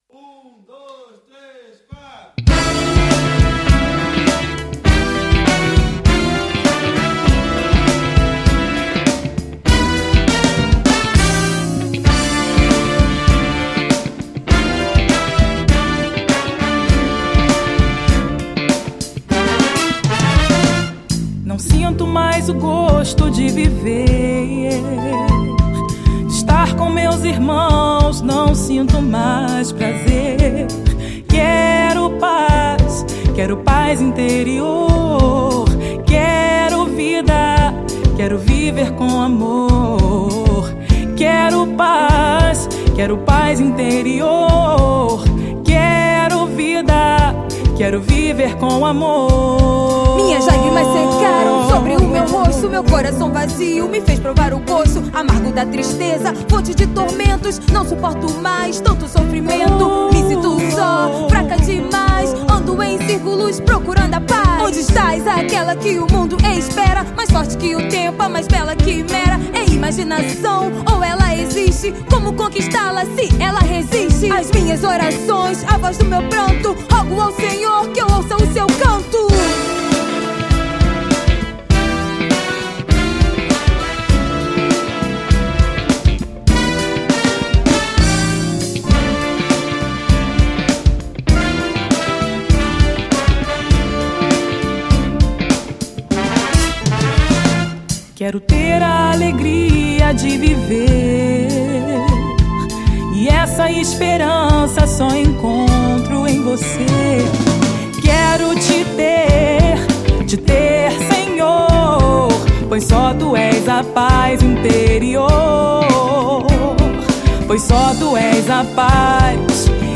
EstiloBlack Music